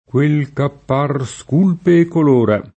scolpire v.; scolpisco [Skolp&Sko], ‑sci — ant. o poet. sculpire: sculpo [Sk2lpo] (con forme modellate su quelle del lat. sculpere [Sk2lpere]: pass. rem. sculsi [Sk2lSi], part. pass. sculto [Sk2lto]): quel ch’a par sculpe e colora [